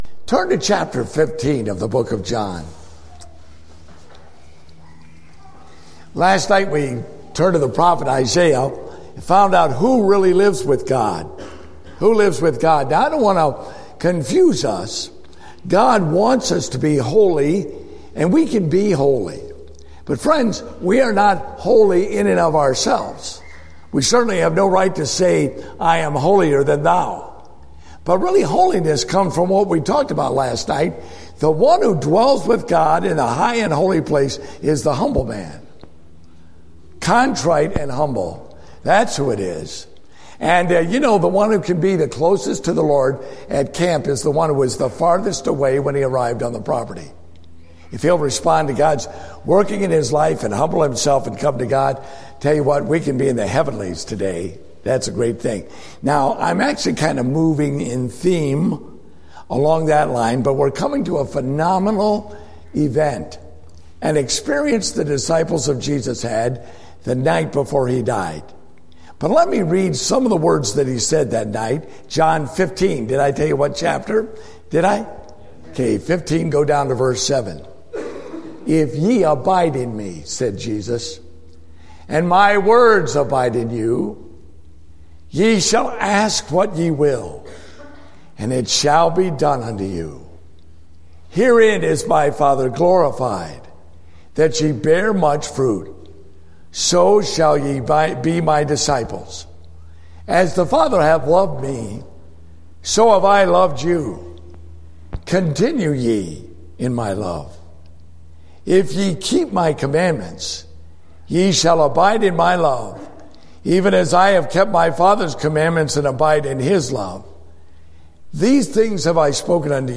Date: August 21, 2015 (Family Camp)